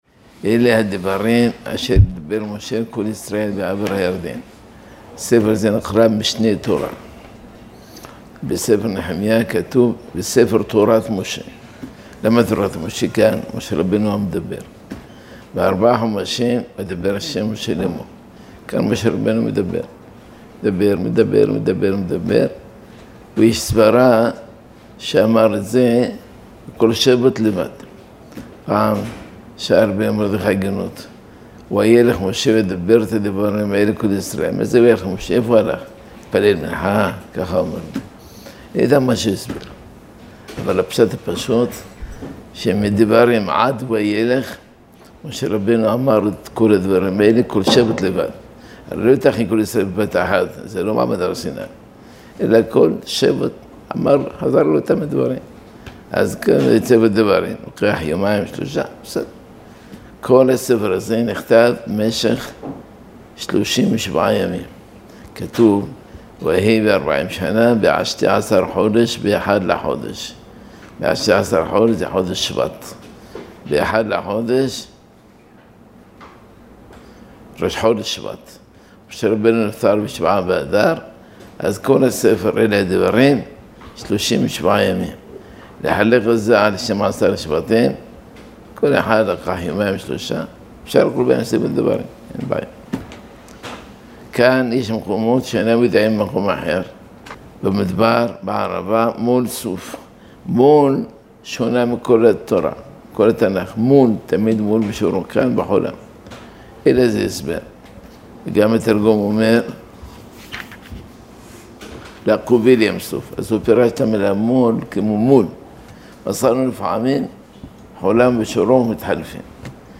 (שיעור זה נמסר בפרשת דברים תשע”ט).